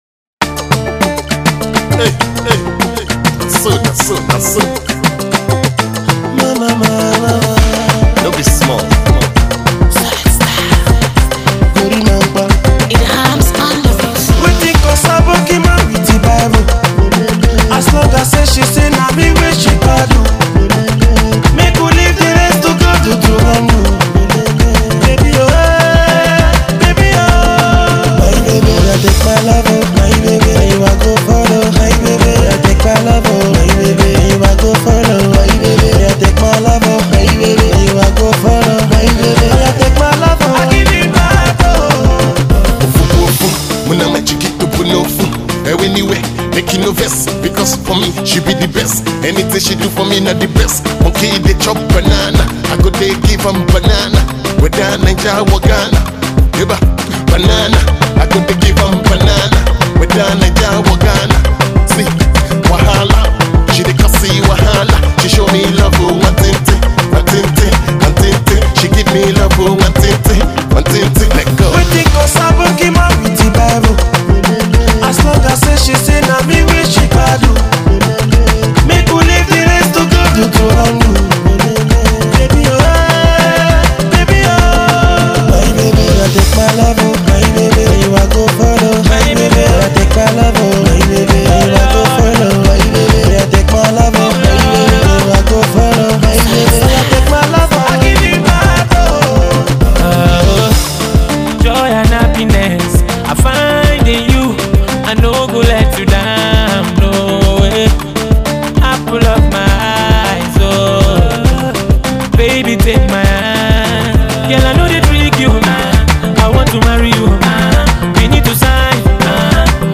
Alternative Pop
Afro-pop